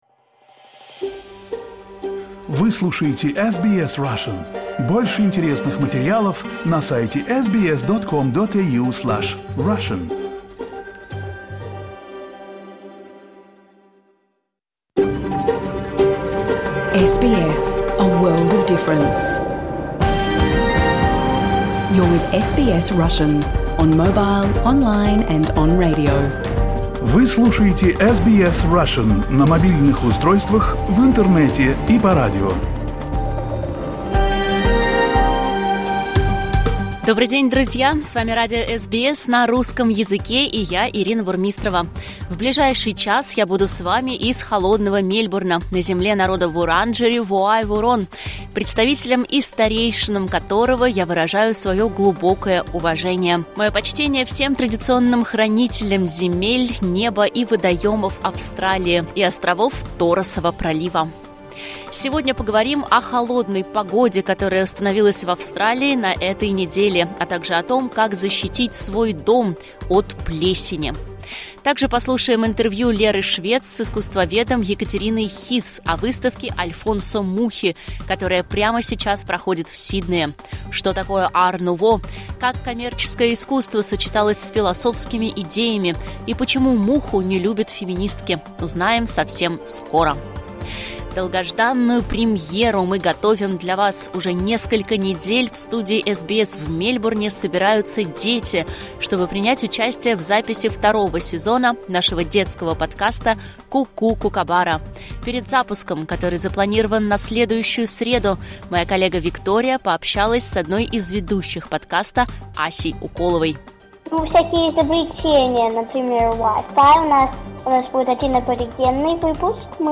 SBS Russian Program — Live 20.07.2024